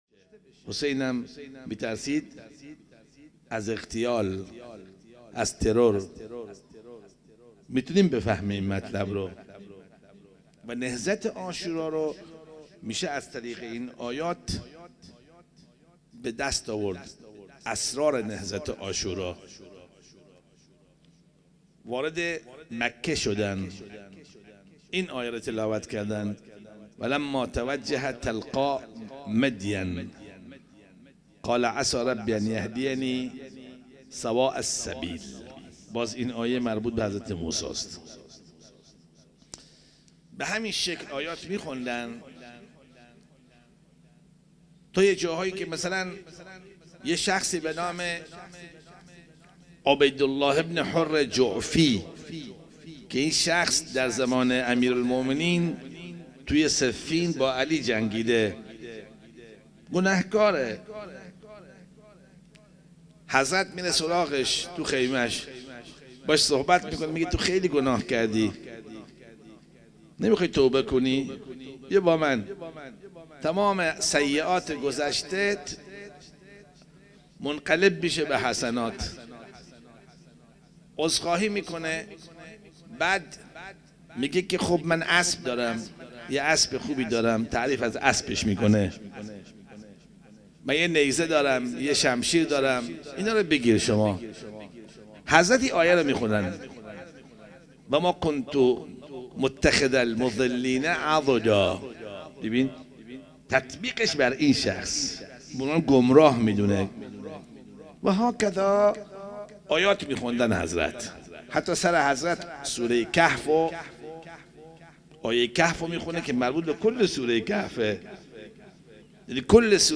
سخنرانی
لیلة 4 محرم الحرام 1439 هـ | هیأت علی اکبر بحرین